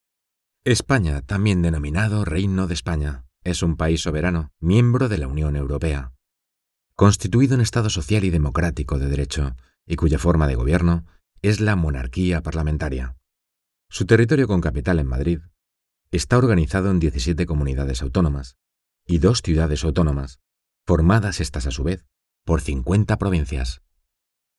Narration
Voz neutral, emotiva, energética y divertida
Profesional Studio at home